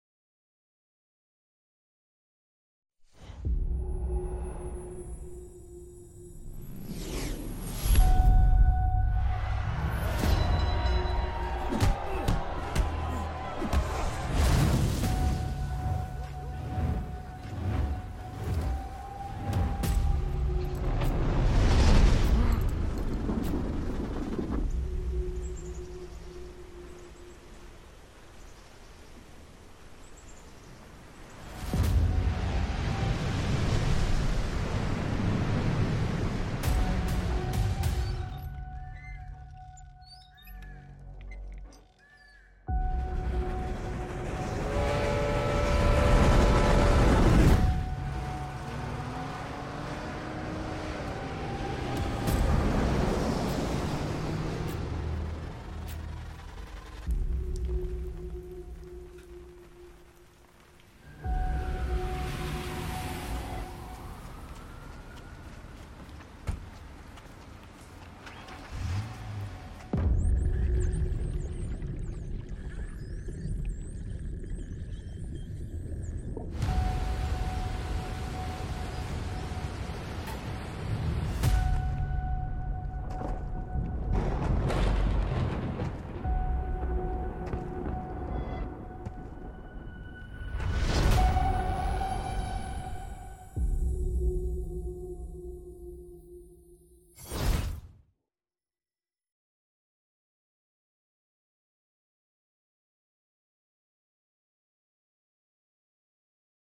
Dolby Atmos Binaural Demo. sound effects free download